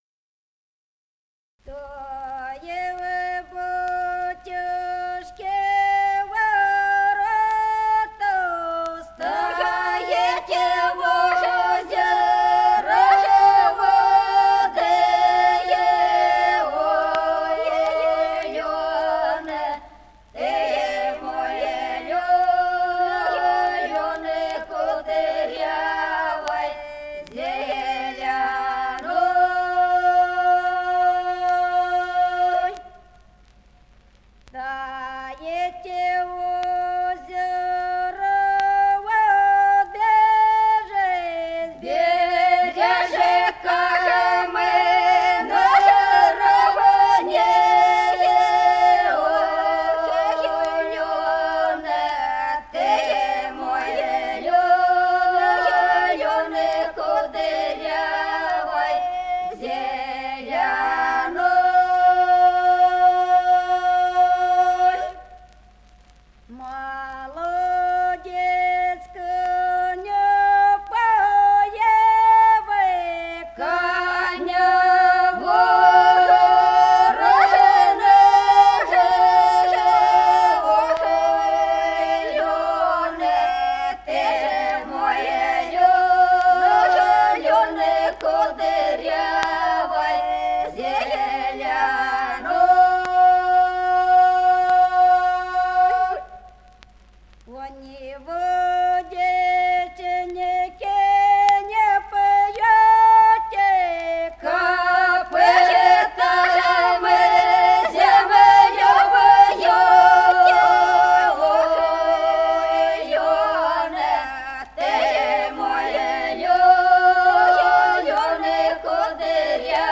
Песни села Остроглядово. Что у батюшки ворот.